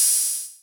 Southside Open Hatz (15).wav